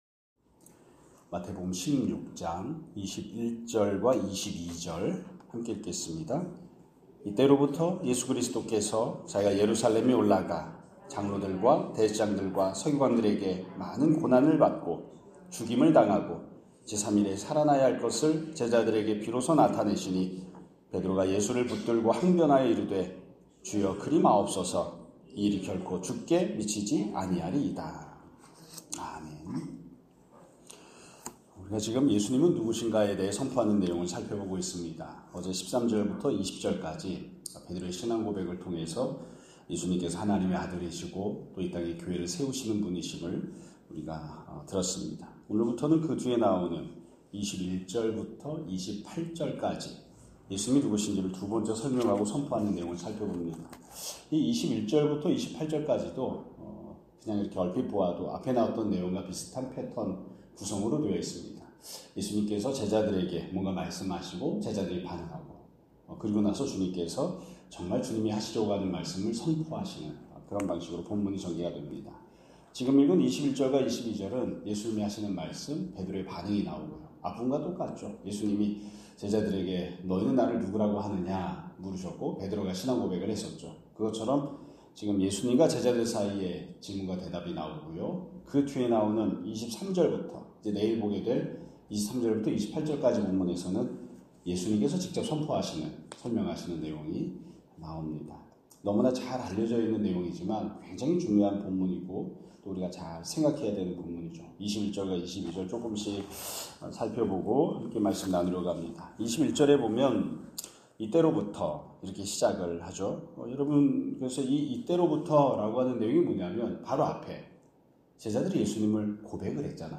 2025년 11월 19일 (수요일) <아침예배> 설교입니다.